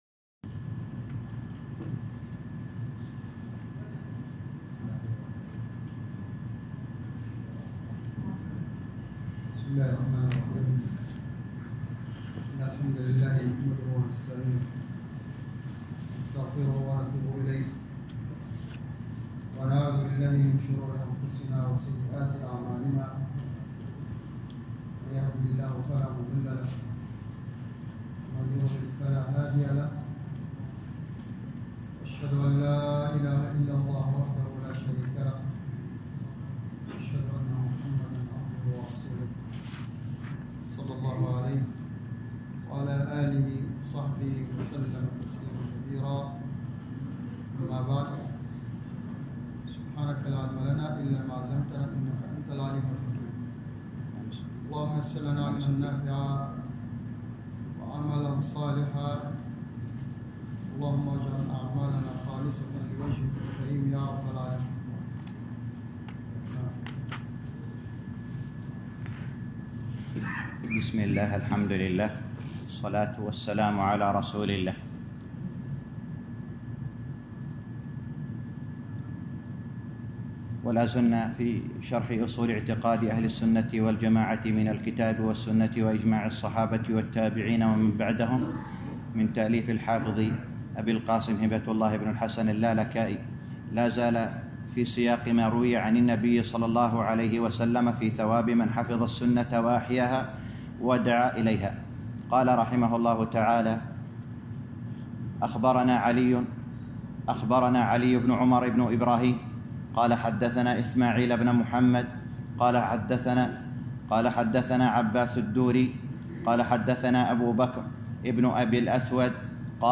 الدرس الثاني عشر - شرح أصول اعتقاد اهل السنة والجماعة الامام الحافظ اللالكائي _ 12